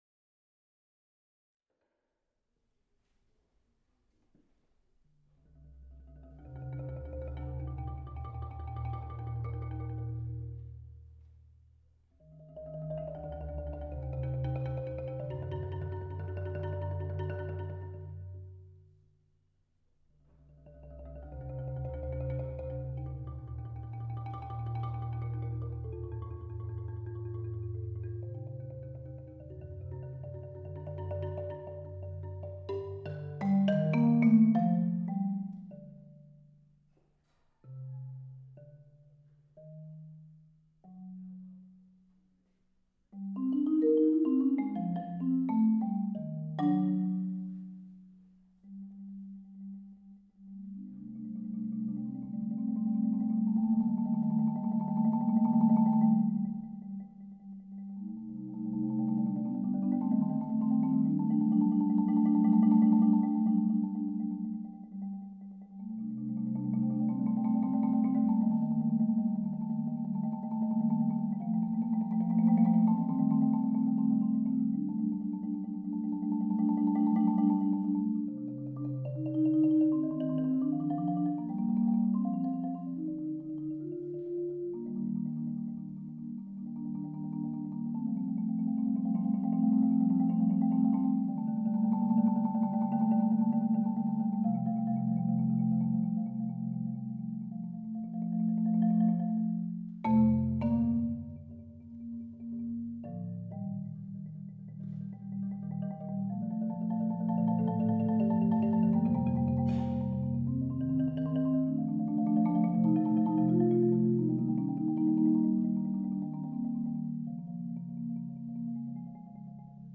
Genre: Marimba (4-mallet)
Solo Marimba (5-octave)